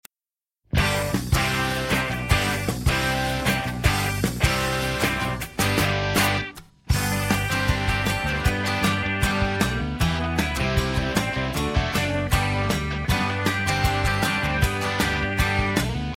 This is an alternative, inclusive, rock 'n' roll anthem.
Listen to a sample of this instrumental song.